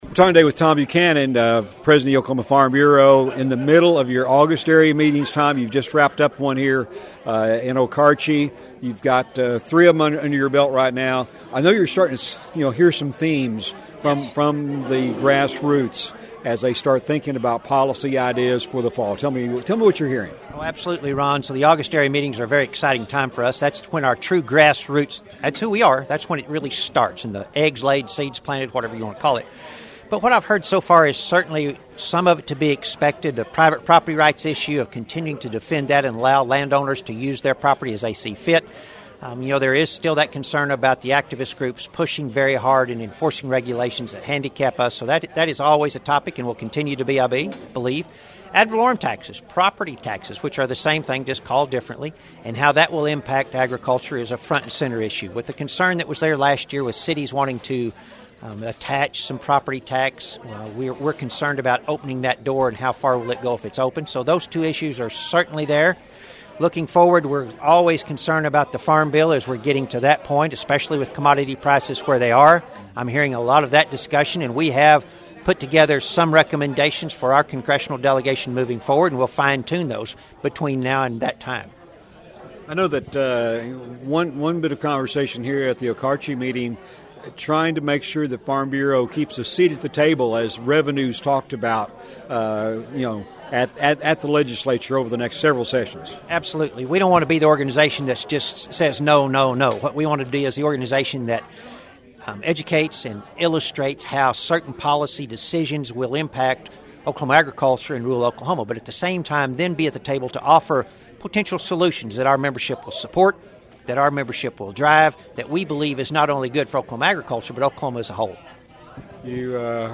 speak about this year's discussion at OFB's Area Meetings